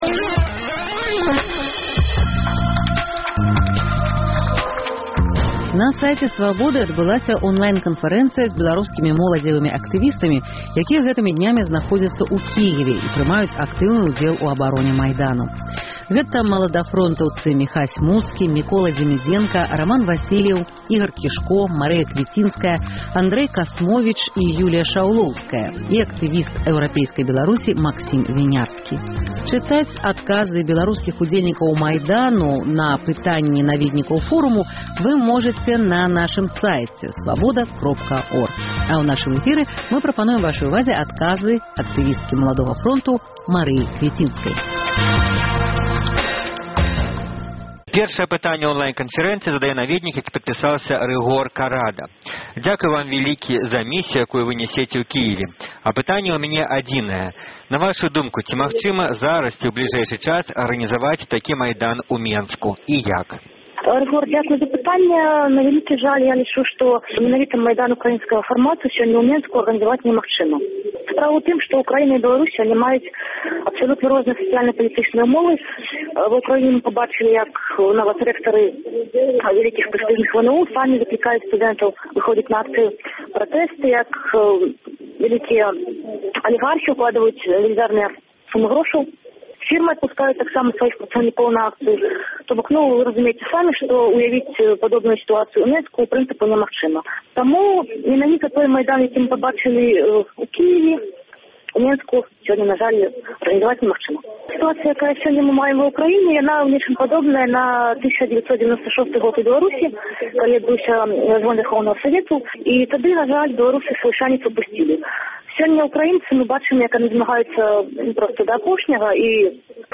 Аўдыёзапіс онлайн-канфэрэнцыі зь беларускімі моладзевымі актывістамі, якія гэтымі днямі знаходзяцца ў Кіеве і прымаюць актыўны ўдзел у абароне Майдану.